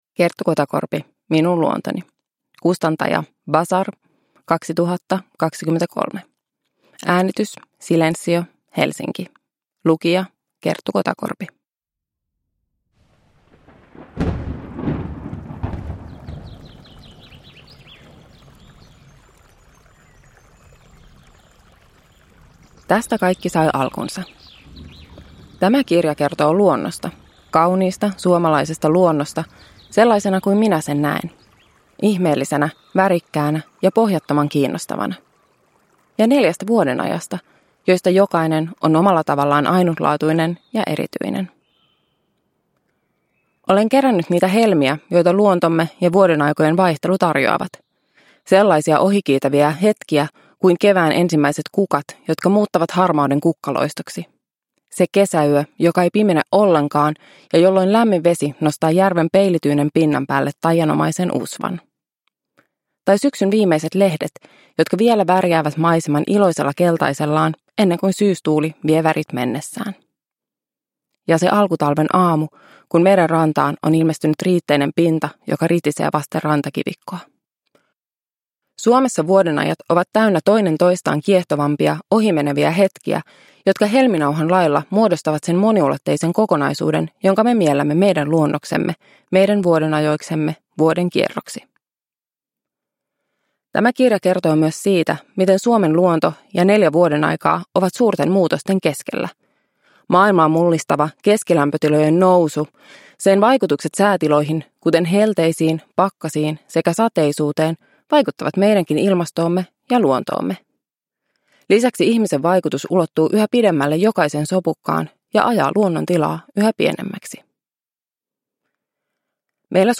Uppläsare: Kerttu Kotakorpi